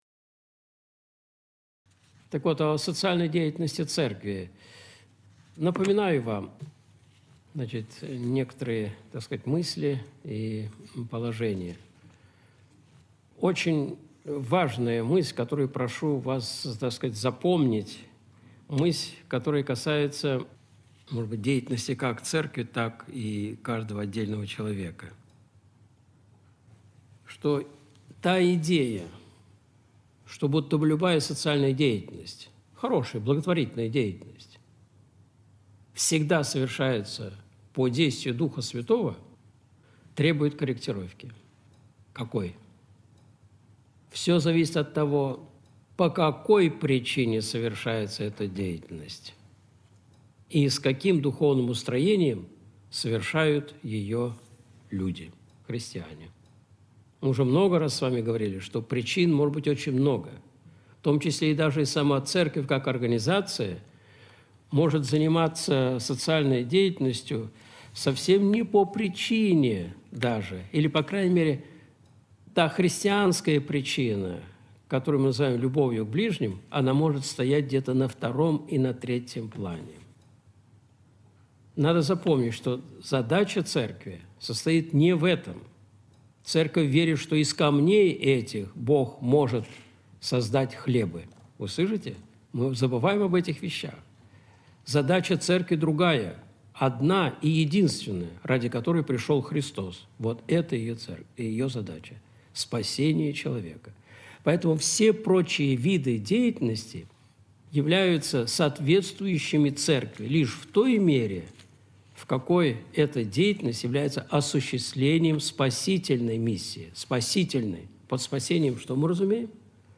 Видеолекции протоиерея Алексея Осипова